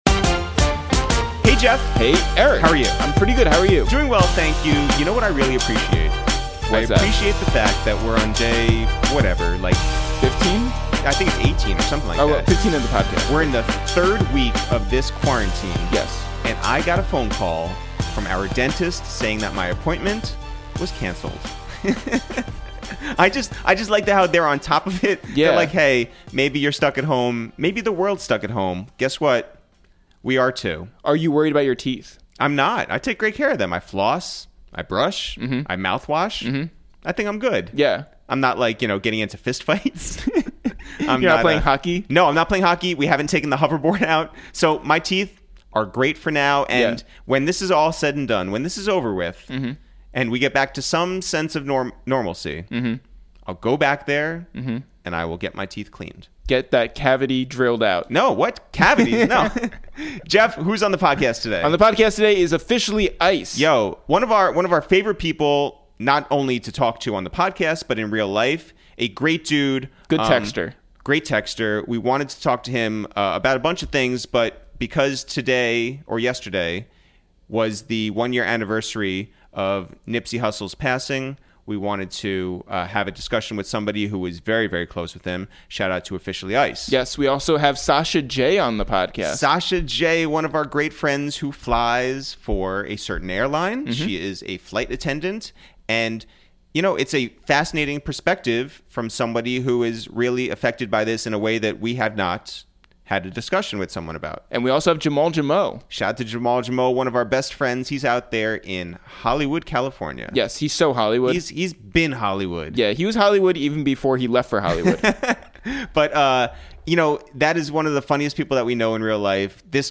we make calls from our Upper West Side apartment